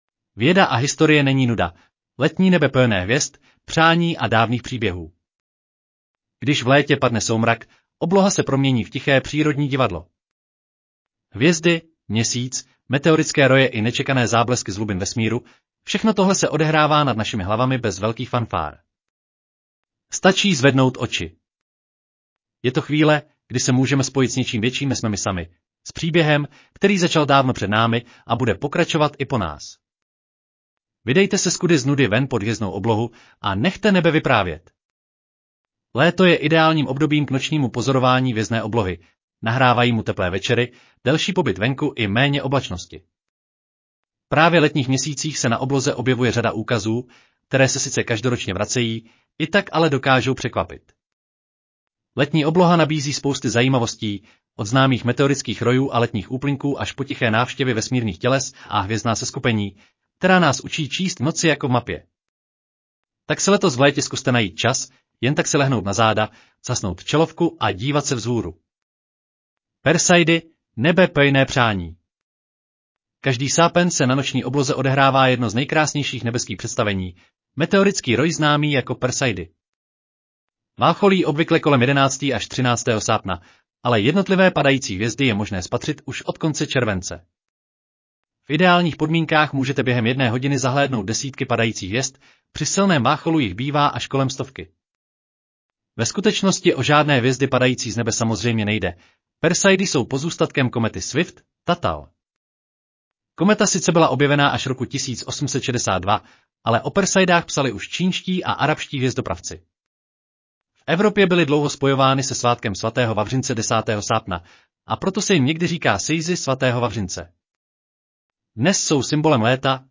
Audio verze článku Věda a historie není nuda: Letní nebe plné hvězd, přání a dávných příběhů